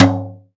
Brush Tom Lo.wav